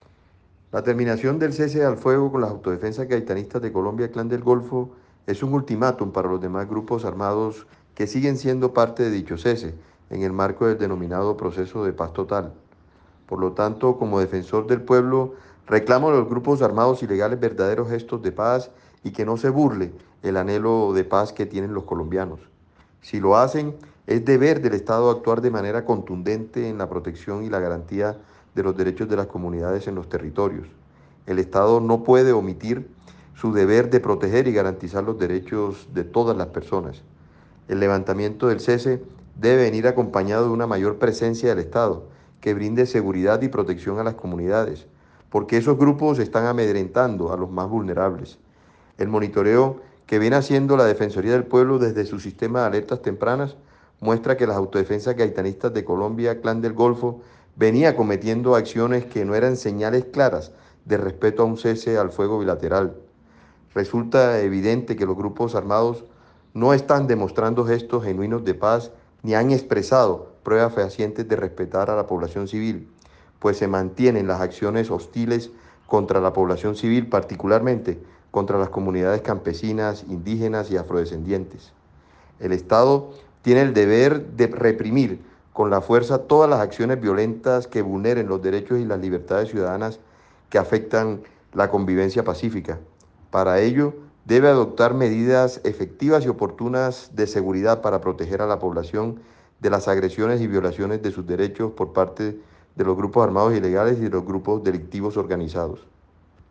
Escuche el pronunciamiento del Defensor del Pueblo, Carlos Camargo.